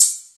07_Hats_04_SP.wav